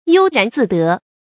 悠然自得 yōu rán zì dé
悠然自得发音
成语正音 得，不能读作“dě”。